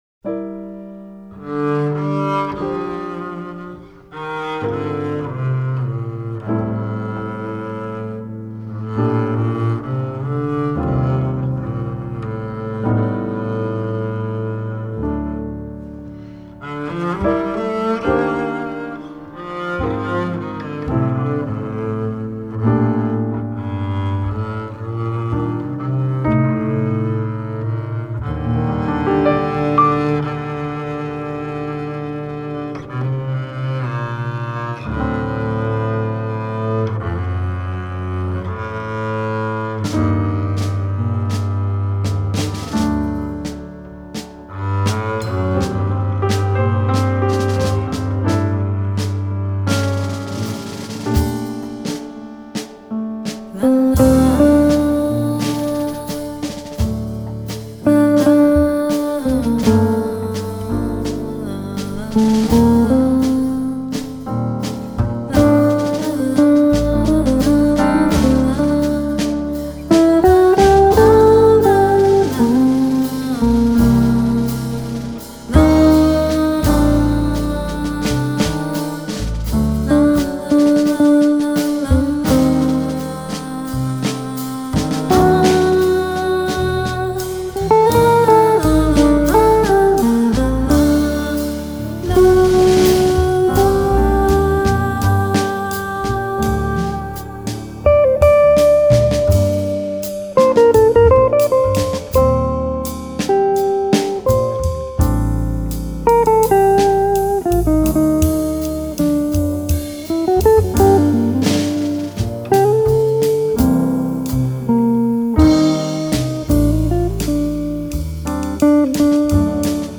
piano, voc